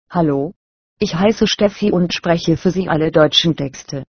Sprecher für das Vorleseprogramm MWS Reader
ScanSoft – RealSpeak Solo für Deutsch – German